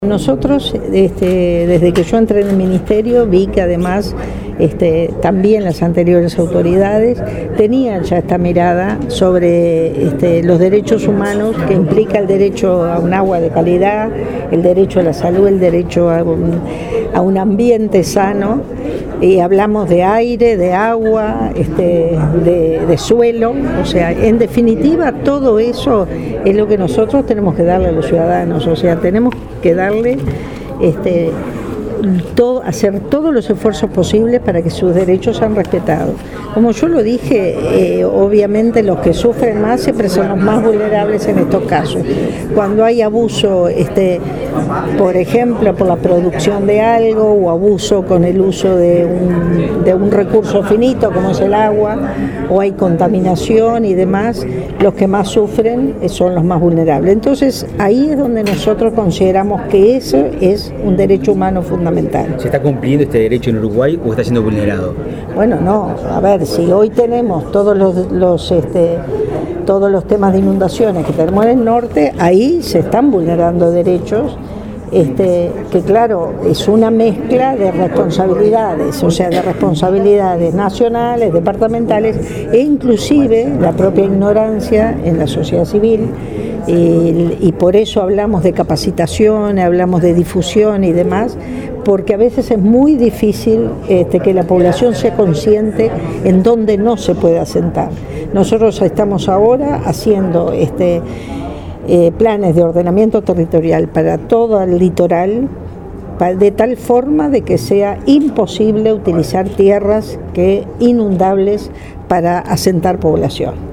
El derecho a un ambiente sano es lo que tenemos que ofrecer al ciudadano, afirmó la ministra de Medio Ambiente, Eneida de León, en la firma del acuerdo de cooperación con la Secretaría de Derechos Humanos.